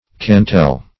cantel - definition of cantel - synonyms, pronunciation, spelling from Free Dictionary Search Result for " cantel" : The Collaborative International Dictionary of English v.0.48: Cantel \Can"tel\ (k[a^]n"t'l), n. See Cantle .